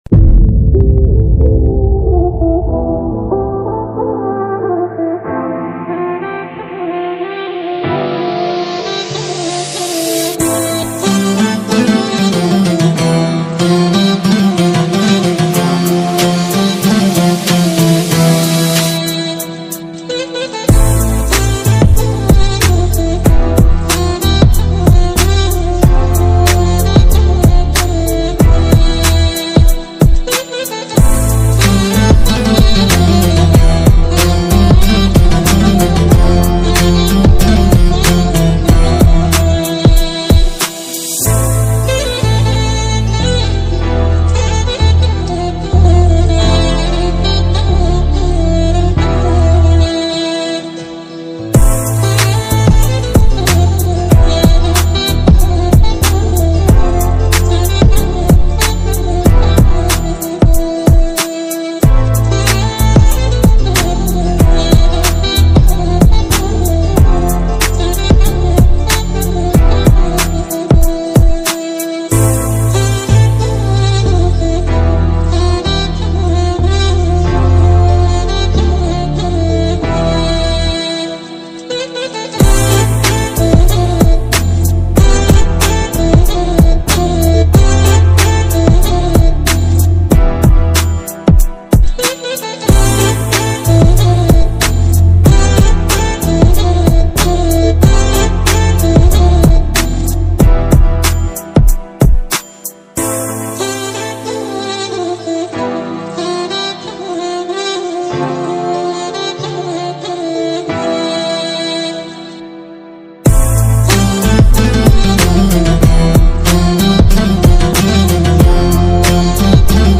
آهنگ زنگ موبایل خفن لاتی پسرانه